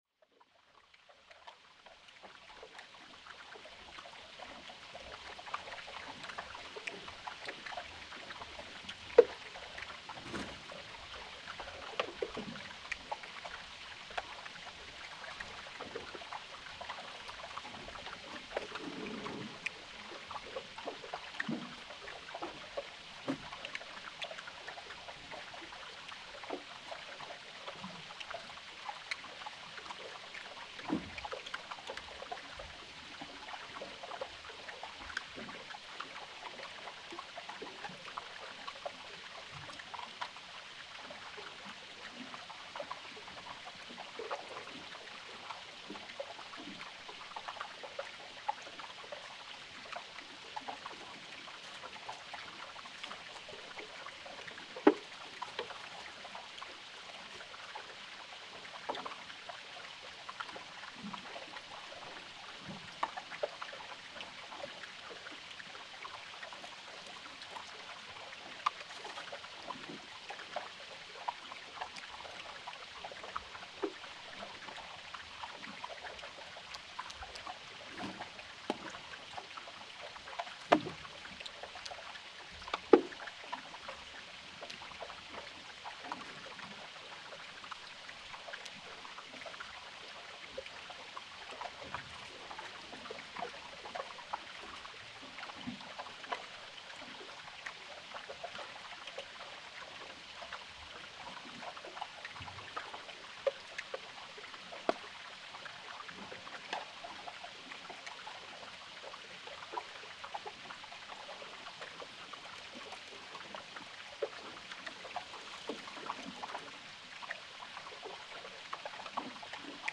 Posted in Náttúra, tagged Aquarian H2a-XLR, Glacier, Glacier lagoon, Hydrophone recording, Jökulsárlón, National park, Sound Devices 788, Vatnajökulsþjóðgarður, Vatnaupptaka, Vinir Vatnajökuls on 6.8.2014| 3 Comments »
I recorded around three hours in the lagoon with hydrophones. I was located close to the ejection because there it was most likely to catch some wild life activity.
It was much louder than I thought and was both stunning and frighten. The several thousand years old ice was melting with cracks, screeches and scratching the bottom of the lake. Sometimes I could detect a deep rumble sound in a distance, probably when icebergs were scratching the bottom of the 200 meters deep lake.
t179_jokulsarlon_part1.mp3